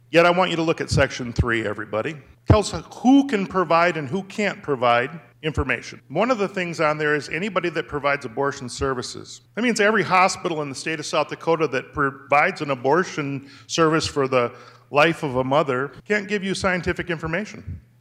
Sen. Jamie Smith, a Democrat from Sioux Falls, said the restrictions in the bill are the problem–and it’s political